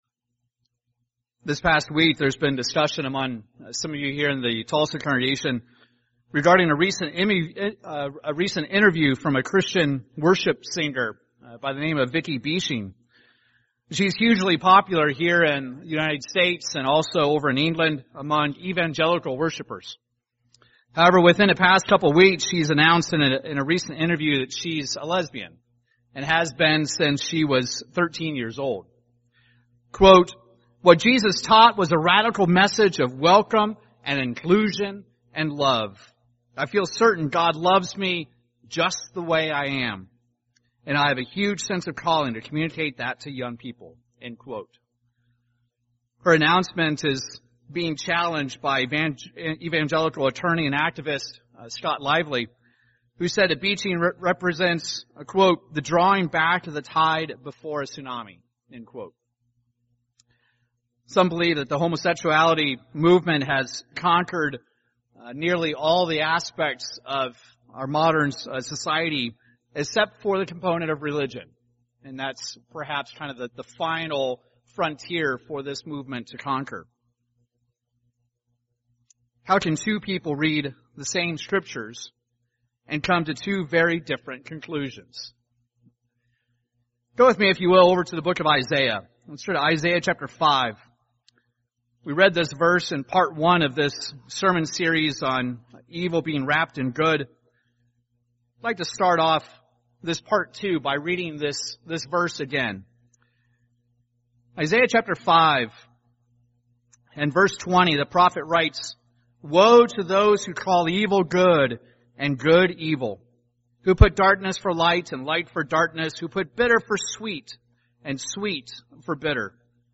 Recorded in Tulsa, OK.
UCG Sermon Studying the bible?